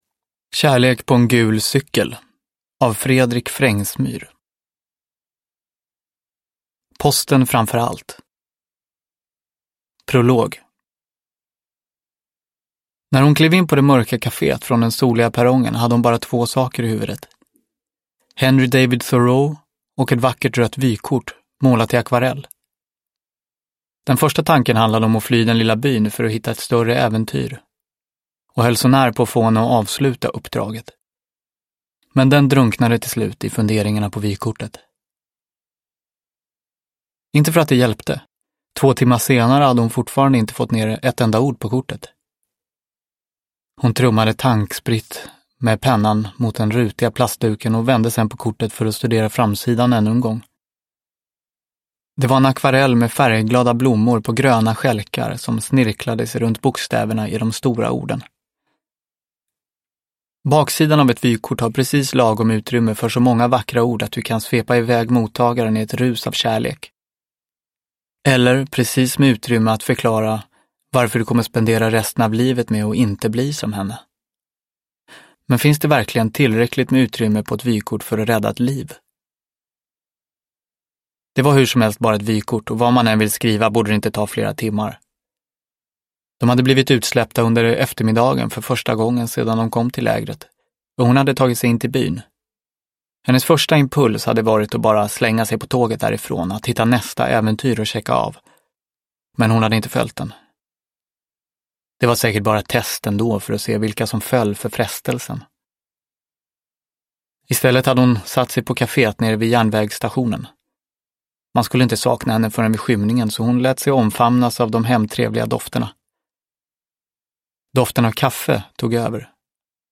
Kärlek på en gul cykel – Ljudbok – Laddas ner